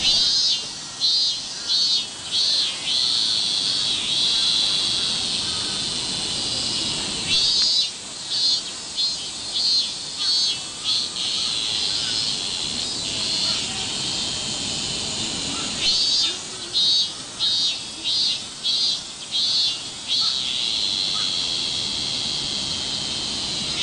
Cicadas sound ringtone free download
Animals sounds